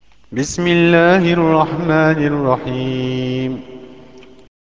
بسم الله الرحمن الرحيم  -  bismi-llāhi r-raḥmāni r-raḥīm, "Au Nom de Dieu : celui qui fait miséricorde, le Miséricordieux : ( version sonore :